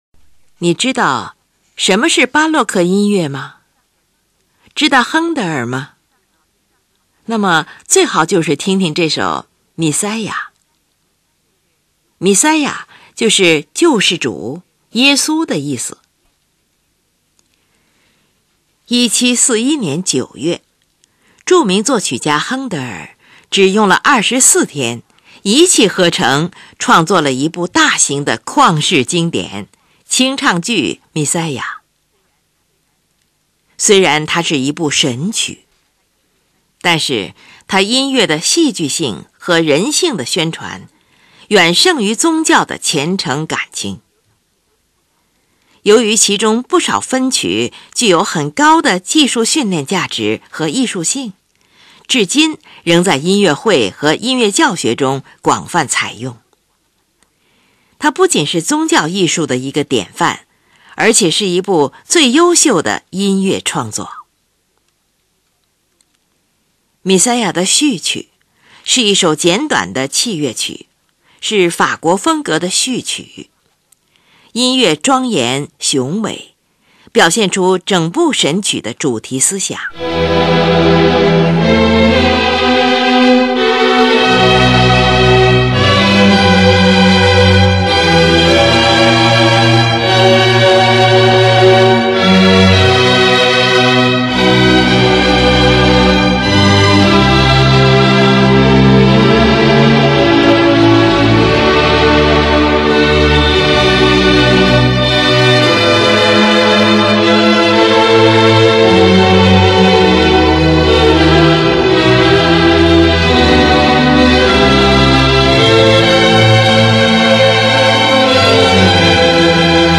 全剧为主调和声音乐风格，以旋律优美、和声洗练见长。